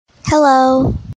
Play Hello Female - SoundBoardGuy
Play, download and share Hello female original sound button!!!!
hello-female.mp3